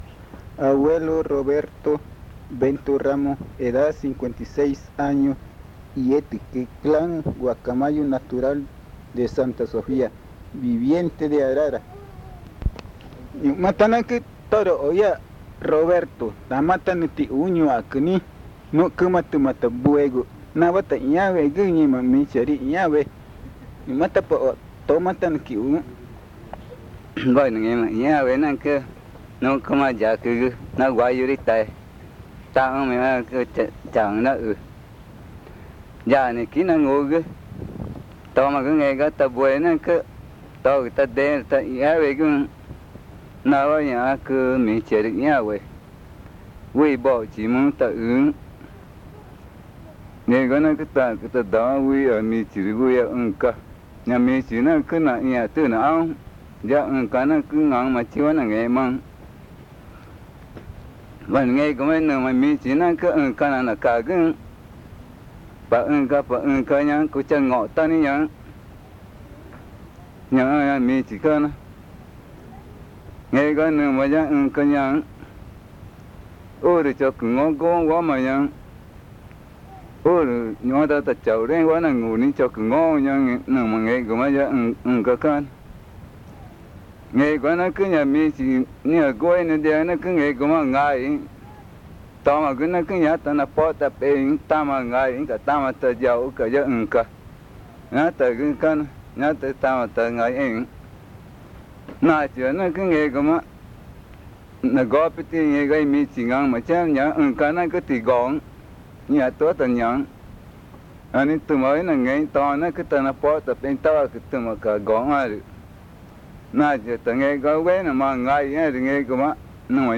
Arara, Amazonas (Colombia)